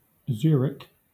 It is pronounced /ˈzjʊərɪk/
ZURE-ik.[12][13][14]